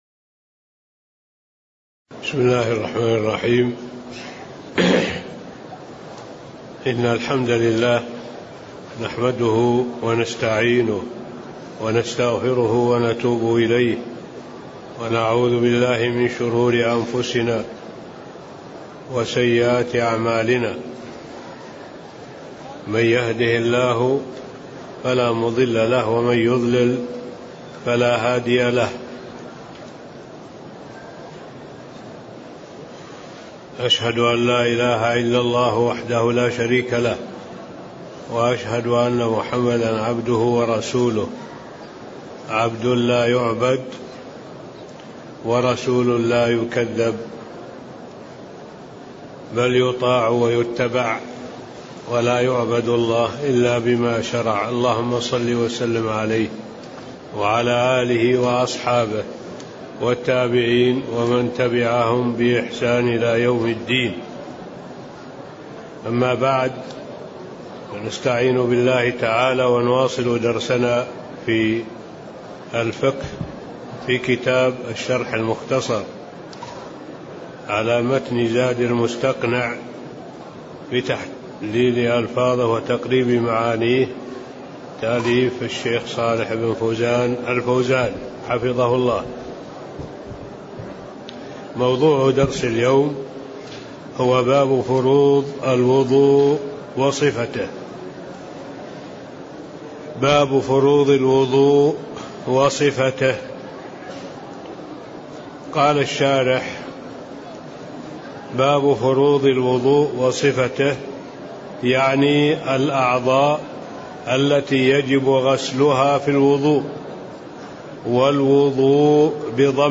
تاريخ النشر ٢ ربيع الثاني ١٤٣٤ هـ المكان: المسجد النبوي الشيخ